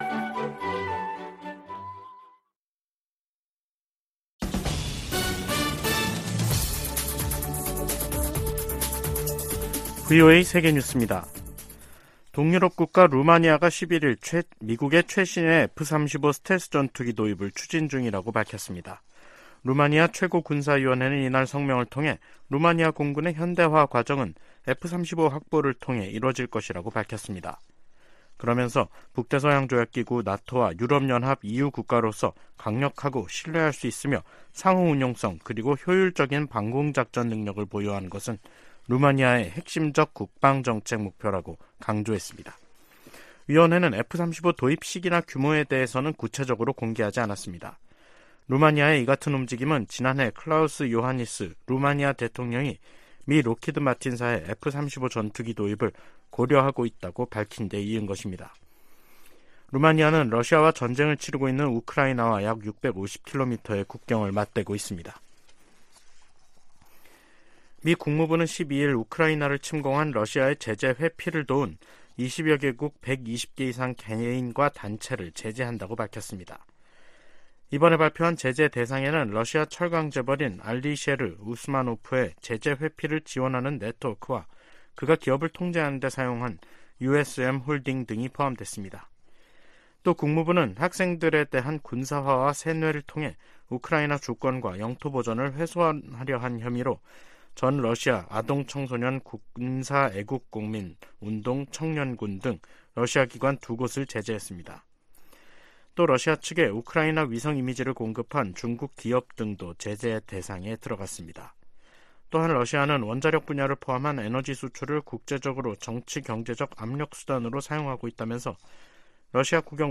VOA 한국어 간판 뉴스 프로그램 '뉴스 투데이', 2023년 4월 13일 2부 방송입니다. 북한이 중거리급 이상의 탄도 미사일을 동해쪽으로 발사했습니다.